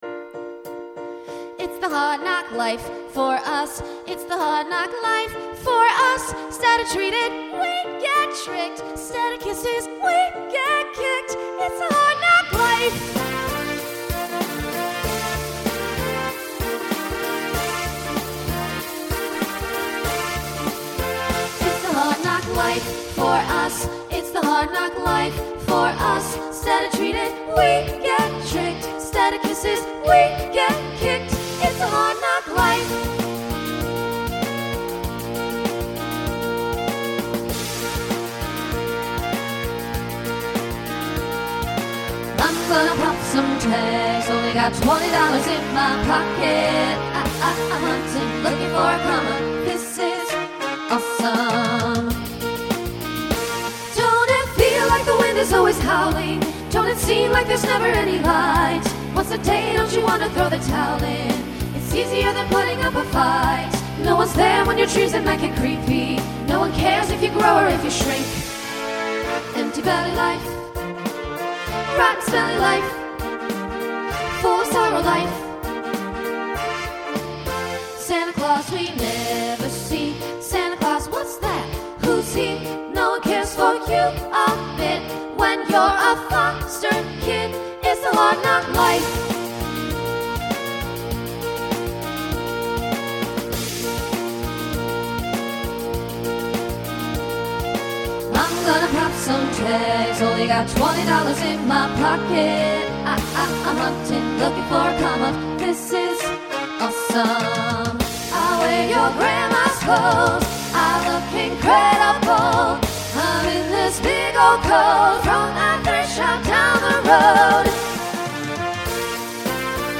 Voicing SSA Instrumental combo Genre Pop/Dance